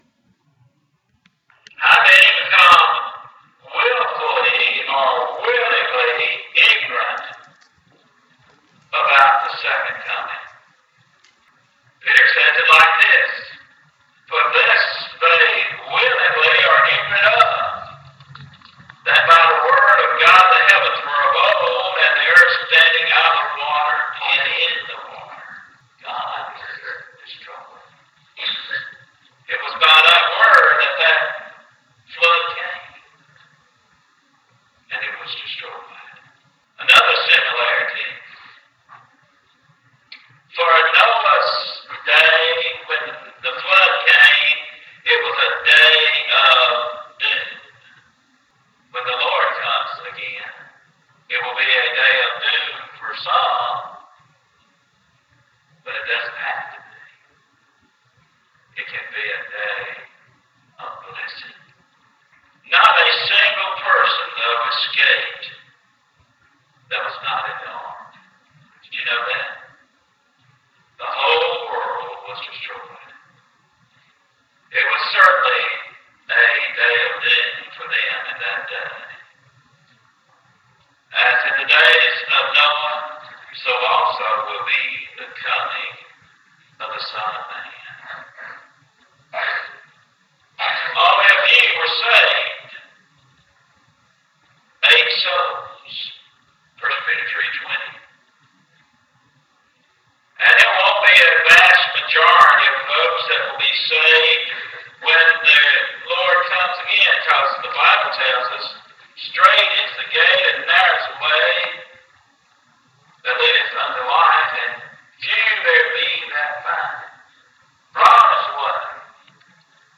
2015-Summer-Sermons-3b.mp3